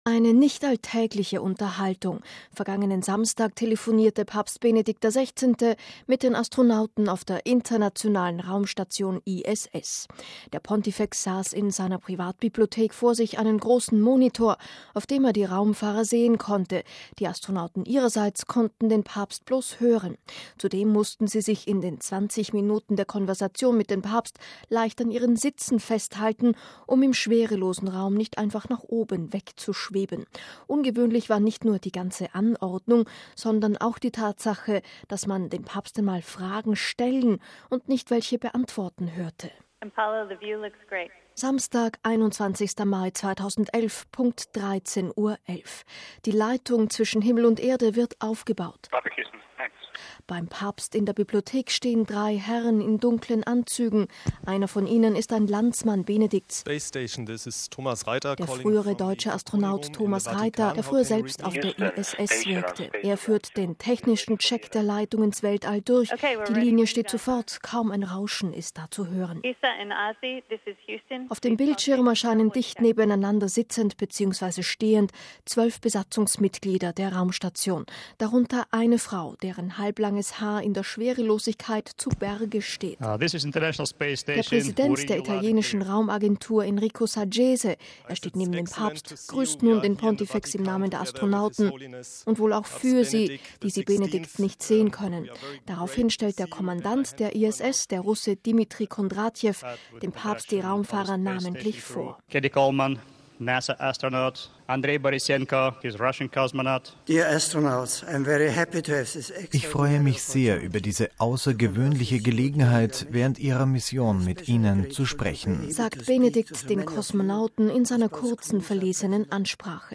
MP3 Eine nicht alltägliche Unterhaltung: Vergangenen Samstag telefonierte Papst Benedikt XVI. mit den Astronauten auf der Internationalen Raumstation ISS.
Beim Papst in der Bibliothek stehen drei Herren in dunklen Anzügen, einer von ihnen ist ein Landsmann Benedikts, der frühere deutsche Astronaut Thomas Reiter, der früher selbst auf der ISS wirkte; er führt den technischen Check der Leitung ins Weltall durch, die Linie steht sofort, kaum ein Rauschen ist da zu hören.